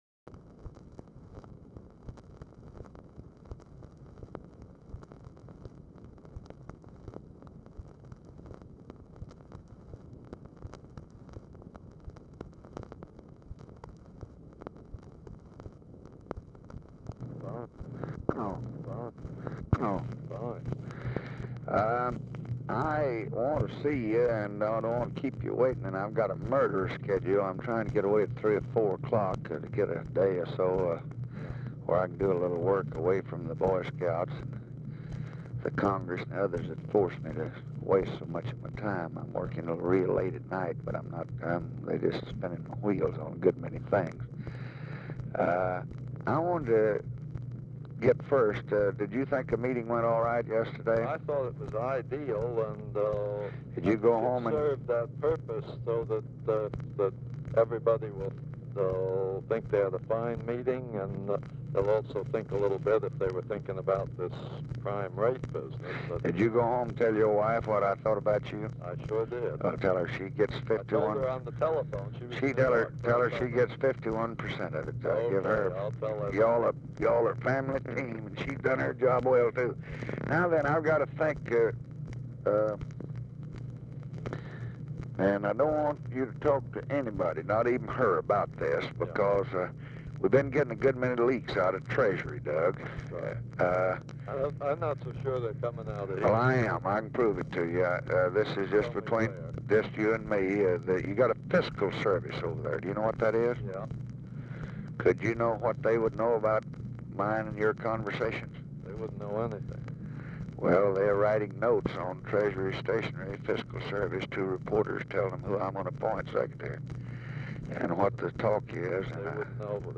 "REPLACEMENT FOR SECY."; RECORDING STARTS AFTER CONVERSATION HAS BEGUN AND ENDS BEFORE IT IS OVER
Format Dictation belt
Specific Item Type Telephone conversation